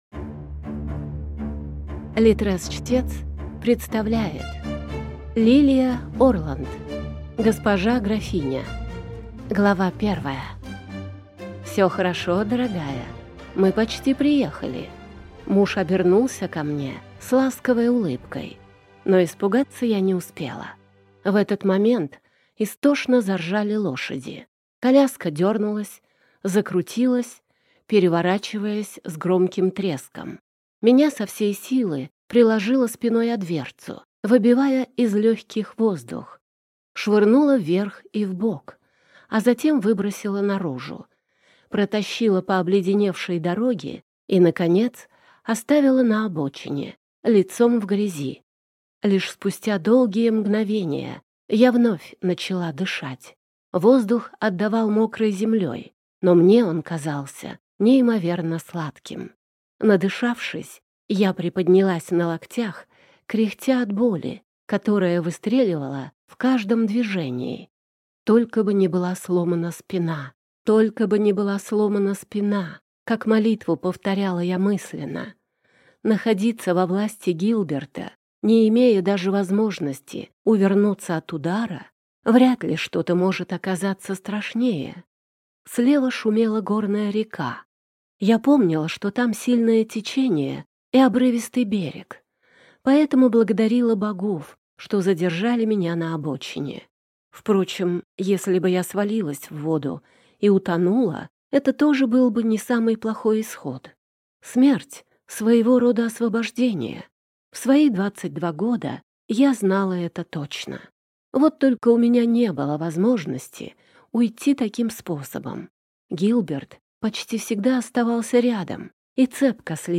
Аудиокнига «Госпожа графиня».